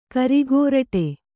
♪ karigōraṭe